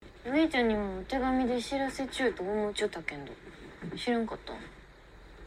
由於柳瀬小時候在四國的高知長大，因此本劇大部分角色皆使用當地方言——土佐方言。